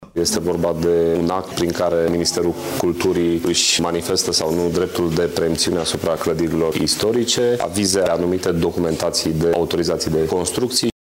Motiv suficient ca multe lucrări imobiliare sau de construcţii din centrul vechi al Brașovului să rămână blocate, întrucât pentru orice autorizație este necesară aprobarea Direcţiei Judeţene de Cultură, adică semnătura directorului, spune consilierul local, Adrian Oprică.